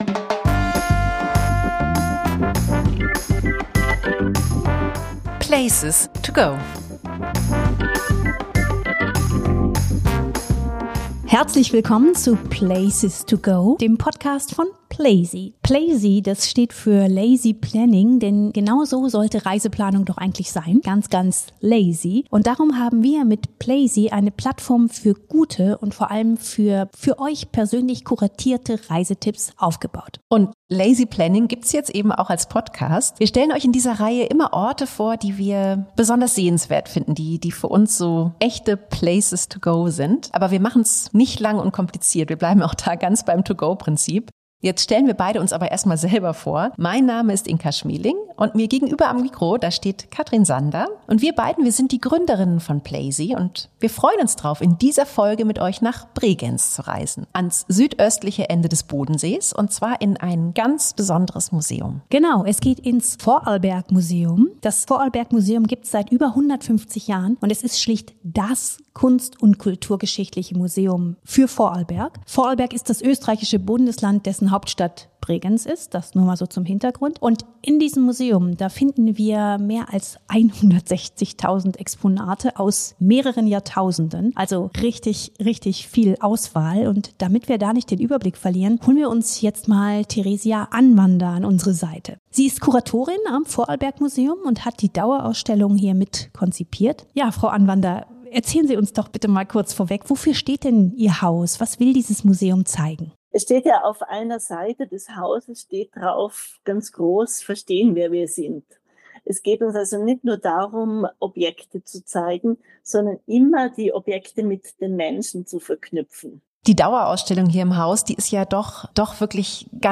Interview-Gast